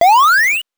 powerup_35.wav